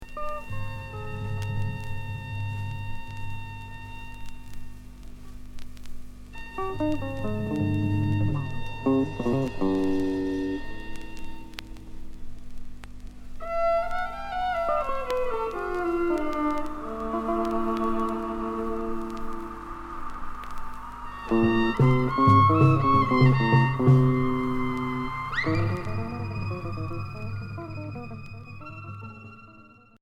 Pop expérimentale Unique 45t retour à l'accueil